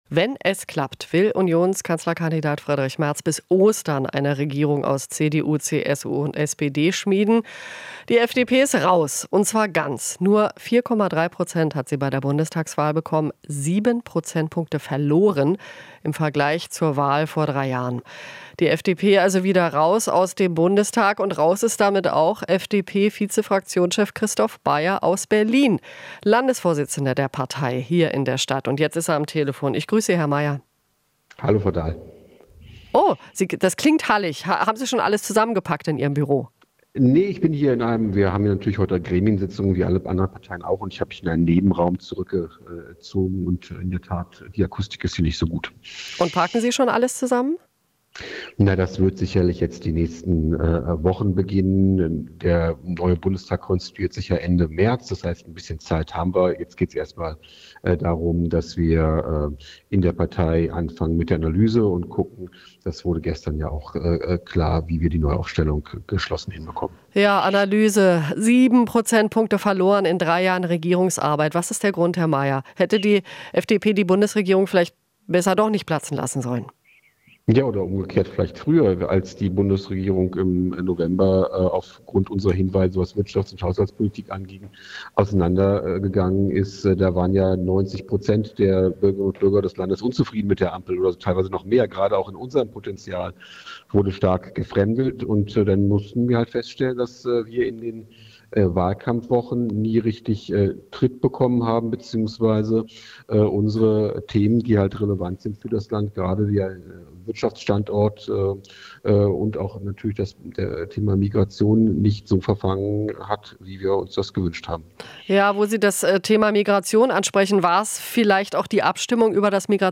Interview - Meyer (FDP): Haben im Wahlkampf nie richtig Tritt bekommen